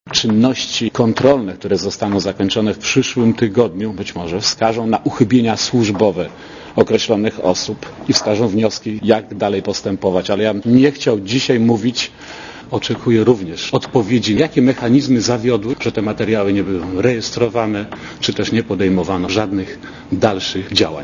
Mówi komendant główny policji
Szreder stwierdził w piątek na konferencji prasowej w Gdańsku, że według niego do prokuratury z CBŚ przekazano już wszystkie dokumenty dot. PZU.